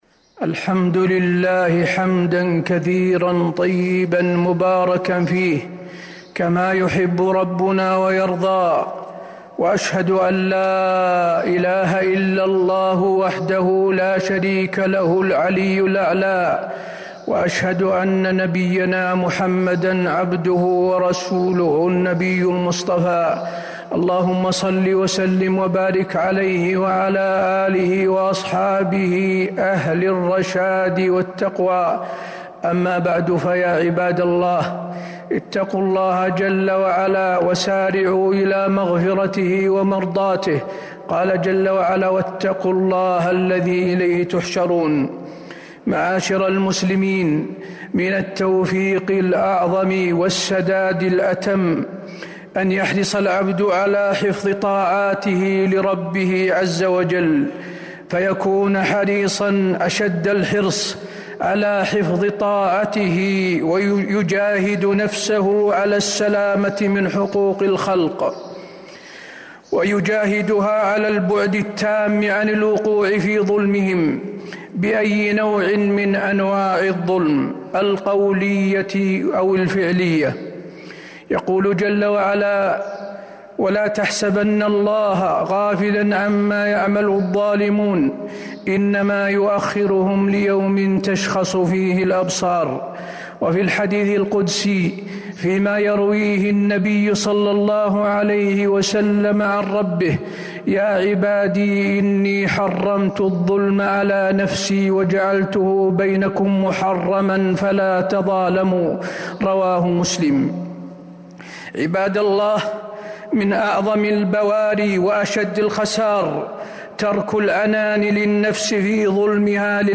المدينة: اتقوا الظلم - حسين بن عبد العزيز آل الشيخ (صوت - جودة عالية. التصنيف: خطب الجمعة